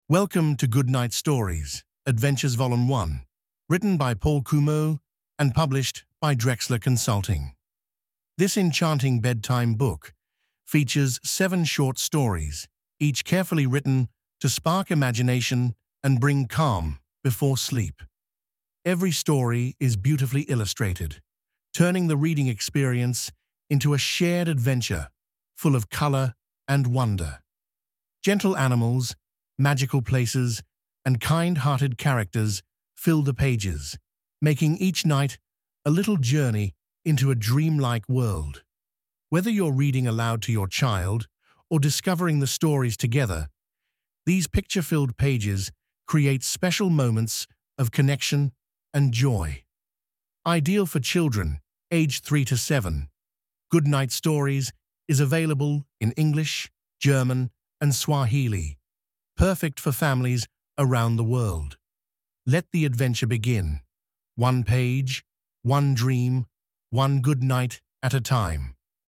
"The Good Night Stories" comprise a series of lovingly narrated tales, each leading into a world full of fantasy and wonder.
With carefully chosen animations and a soft narrative voice, it gives a glimpse of how these stories accompany children on a soothing journey to sleep.